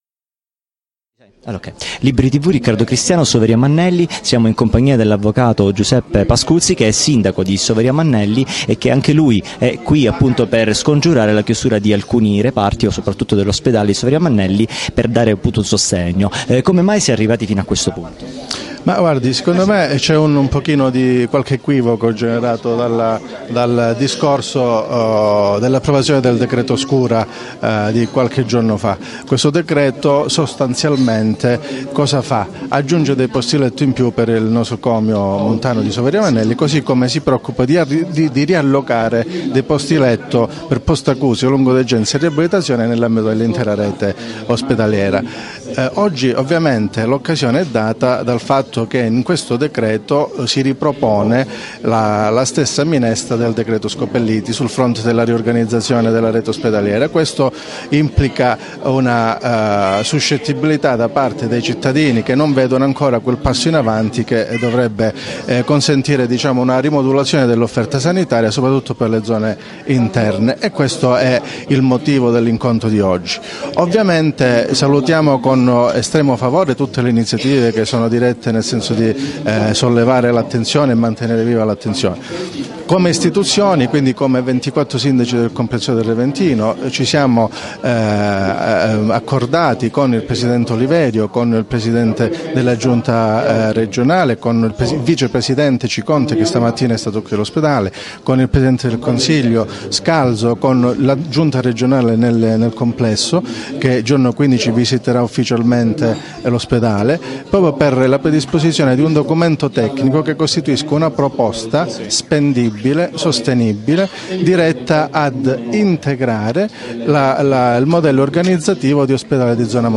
Intervista all'Avv. Giuseppe Pascuzzi Sindaco di Soveria Mannelli - Sit In Ospedale di Soveria Mannelli (CZ)
Manifestazione per la salvaguardia dell'Ospedale del Reventino a Soveria Mannelli (CZ), 4 maggio 2015.
Intervista all'Avvocato Giuseppe Pascuzzi, Sindaco della città di Soveria Mannelli.